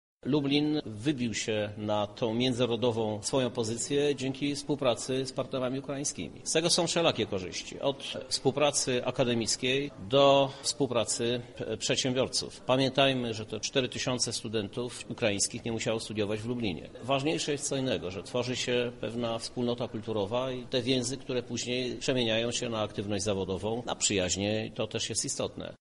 Naszym strategicznym interesem jako regionu jest zbudowanie dobrej współpracy z partnerami z Ukrainy — dodaje odznaczony: